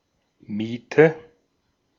Ääntäminen
Ääntäminen Tuntematon aksentti: IPA: [ˈmiːtə] IPA: /ˈmiːtən/ Haettu sana löytyi näillä lähdekielillä: saksa Käännös 1. alquiler {m} 2. renta {f} Artikkeli: die .